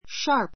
sharp ʃɑ́ː r p